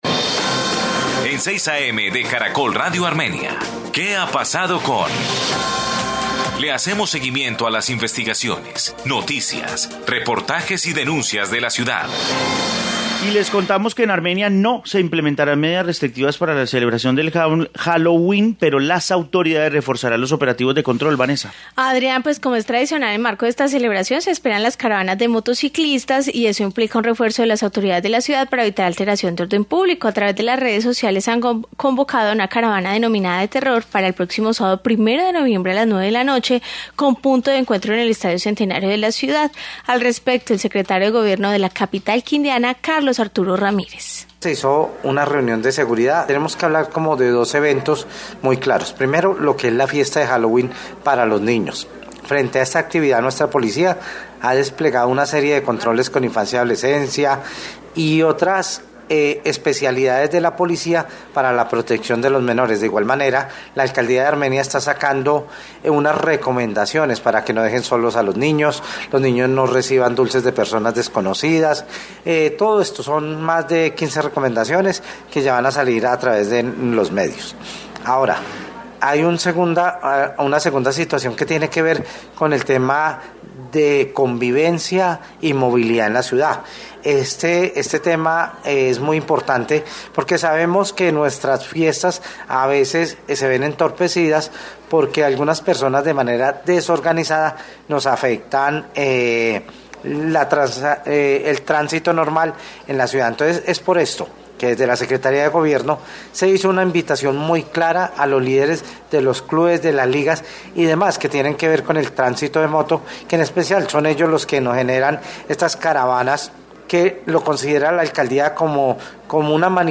Informe sobre celebración de Halloween